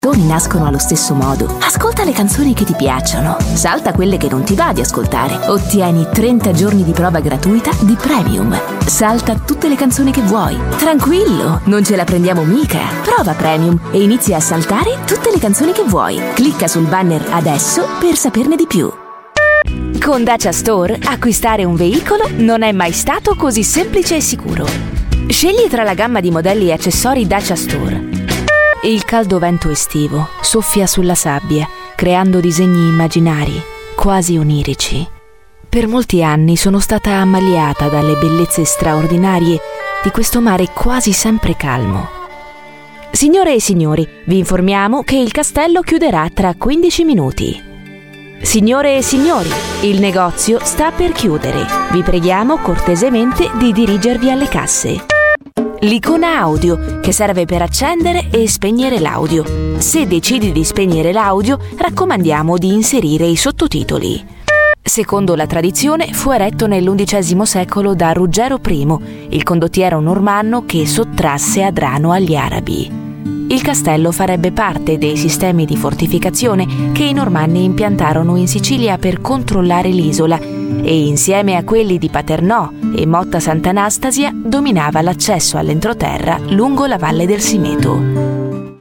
Italian Voice Over